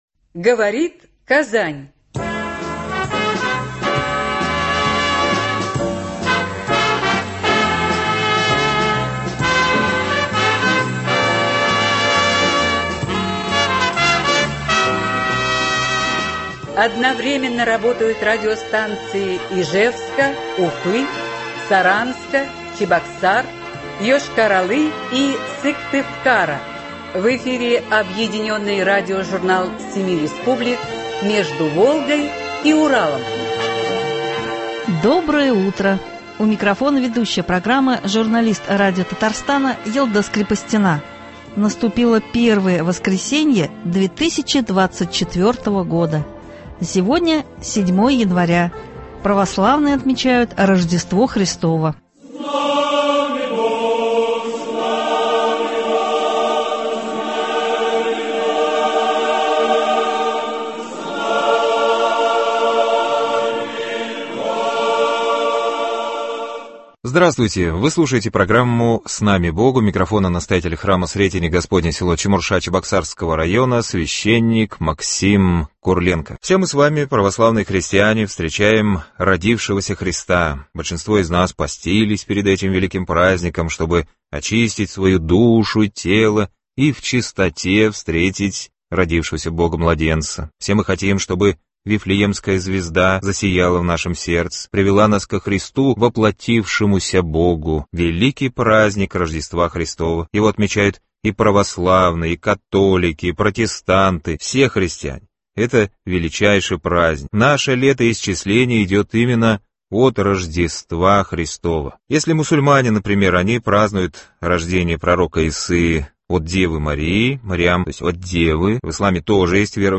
Объединенный радиожурнал семи республик, сегодня выпуск посвящен Рождеству Христову.